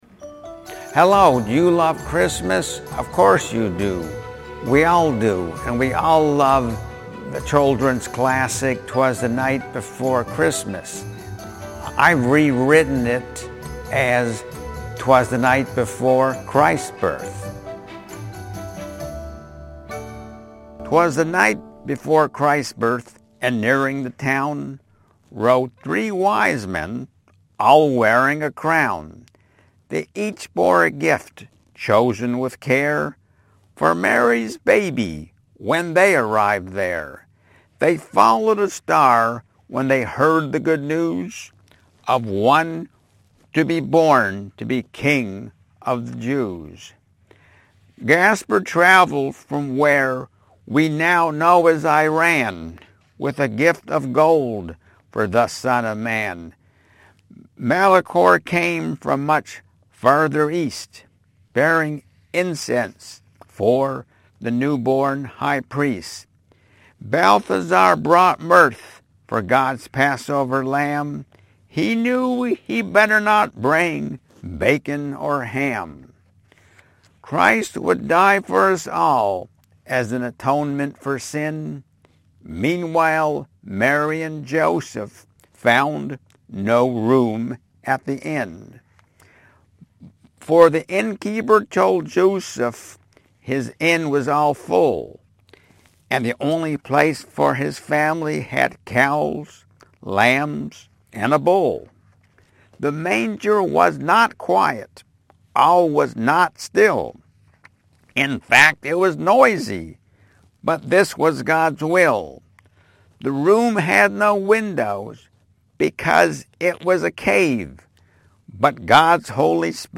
Reading 'TWAS THE NIGHT BEFORE CHRIST'S BIRTH